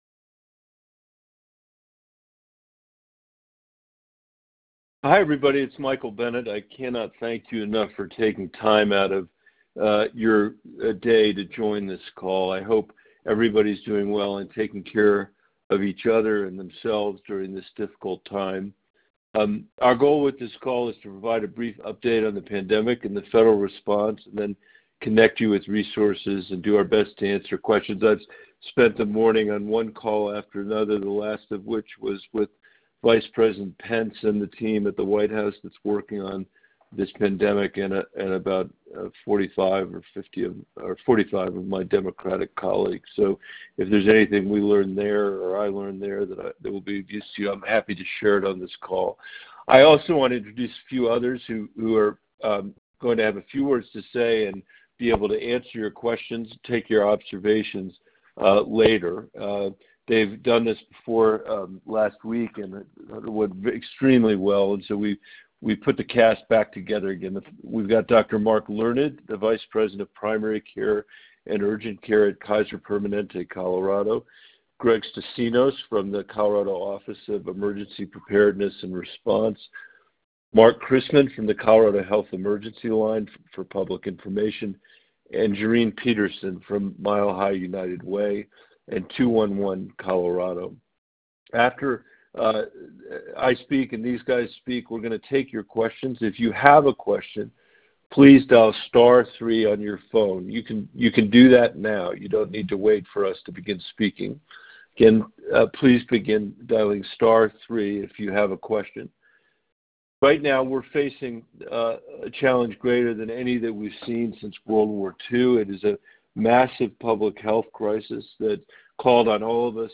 AUDIO: Bennet Holds Coronavirus Telephone Town Hall with Coloradans - U.S. Senator Michael Bennet
To listen to the telephone town hall conversation, please click HERE.